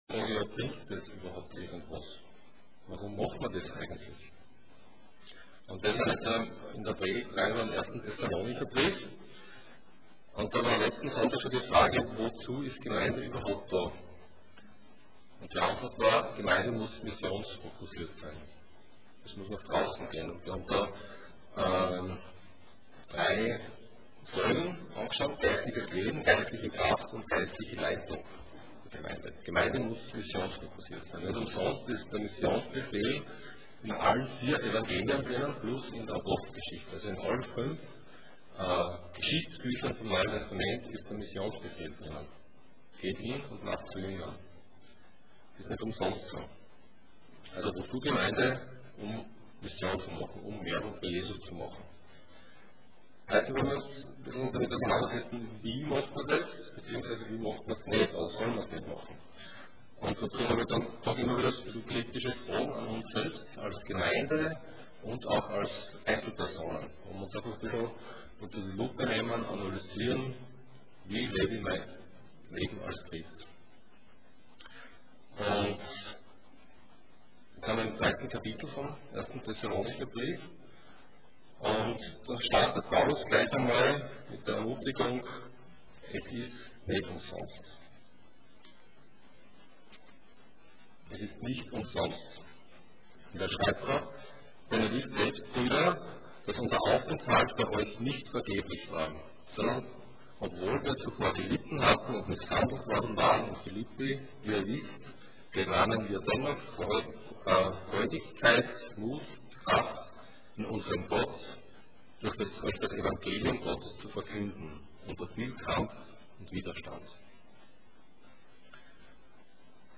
Gnade und Dank Passage: 1 Thessalonians 2:1-16 Dienstart: Sonntag Morgen %todo_render% Wie leben wir als Christen?